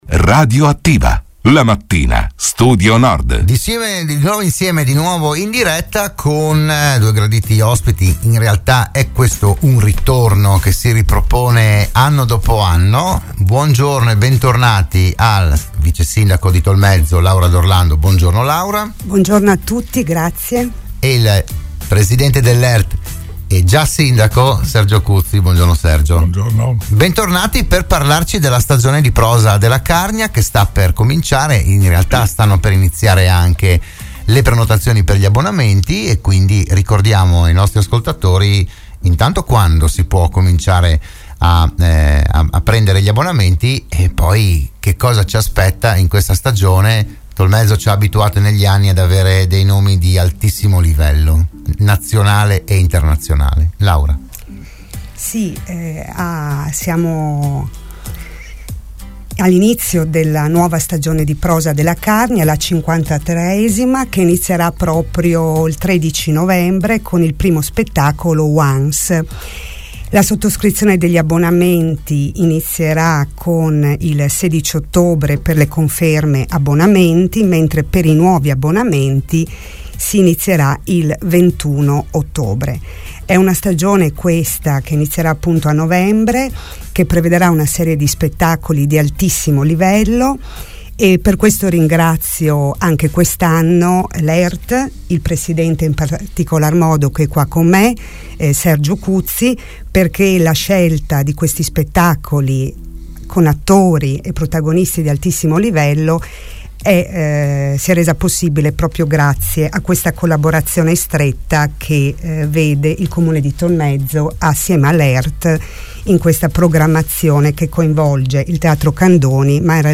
Oggi a Radio Studio Nord ne hanno parlato la vicesindaco di Tolmezzo Laura D'Orlando